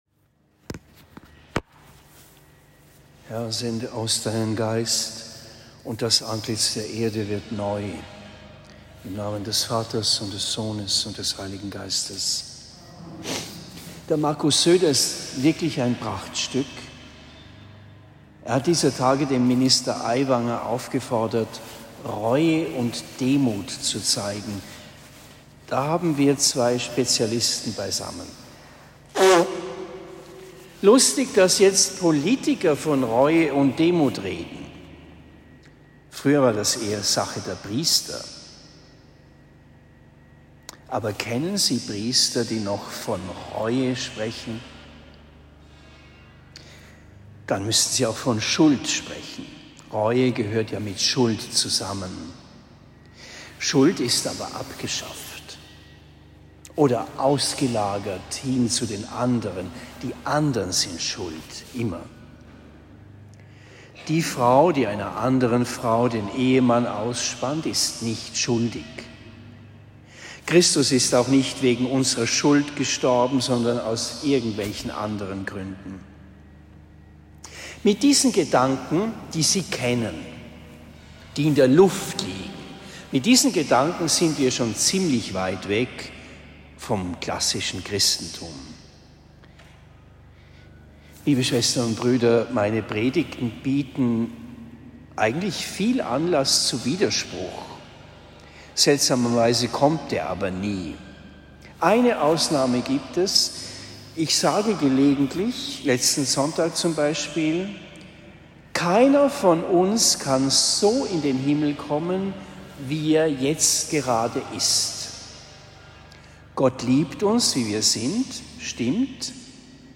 Predigt in Oberndorf am 07. September 2023